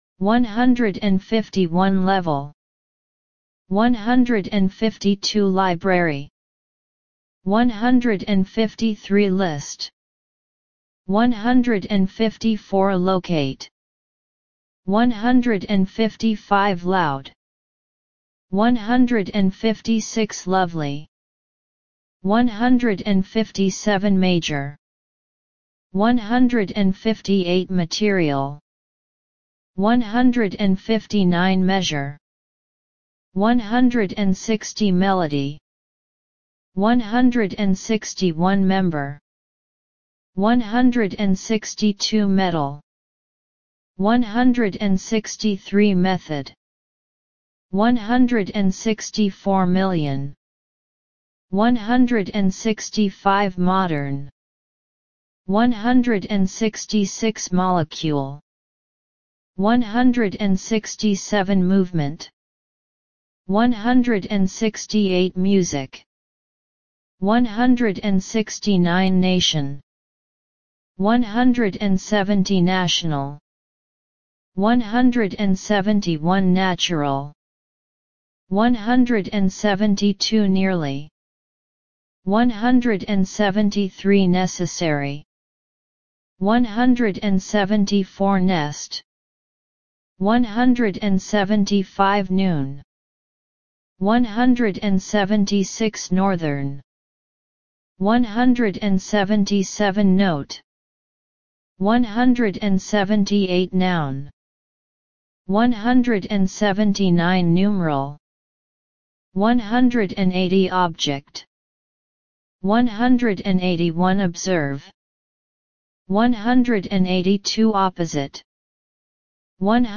150 – 200 Listen and Repeat